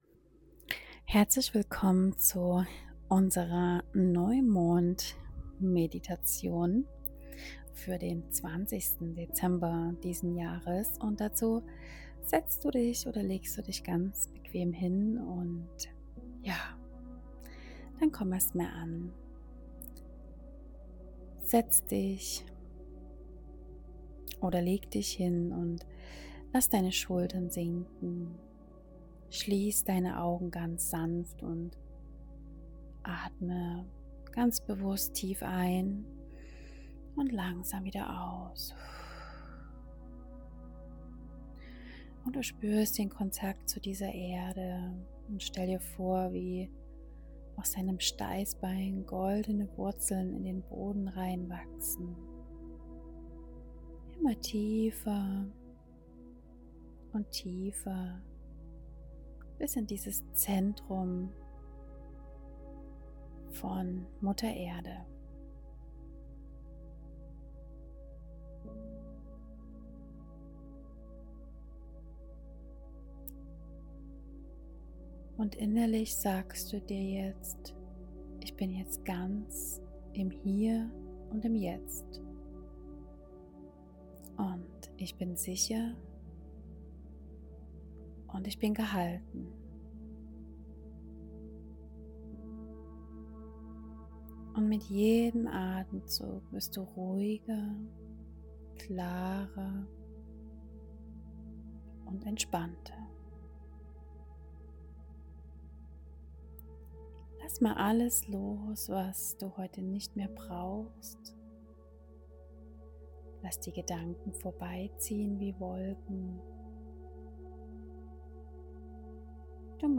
Diese geführte Meditation zum kraftvollen Schütze-Neumond am 20. Dezember öffnet ein energetisches Tor zur Manifestation deiner höchsten Vision.